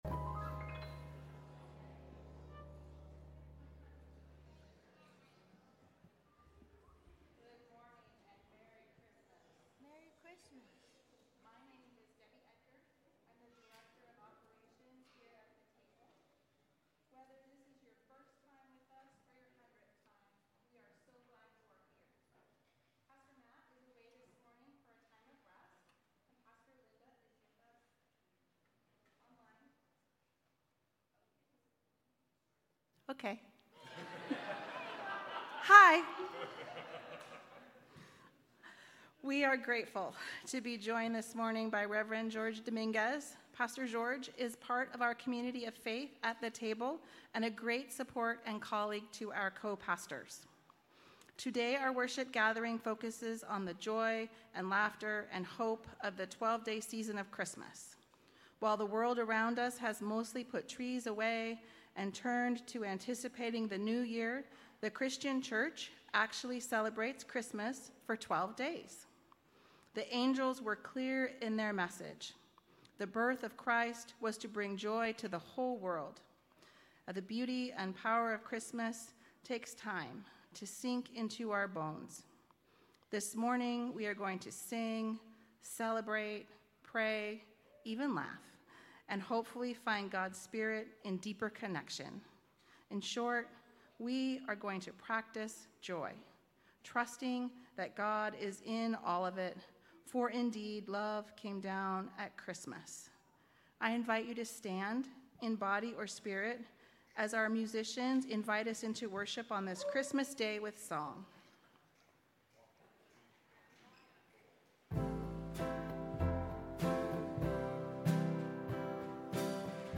Sermons – The Table UMC